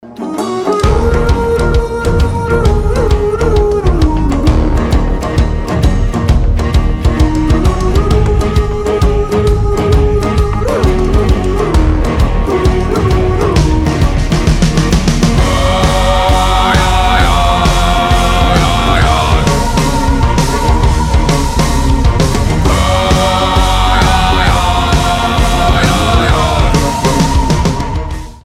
• Качество: 320, Stereo
брутальные
инструментальные
Народные
Folk Rock
этнические
эпичные
Монгольские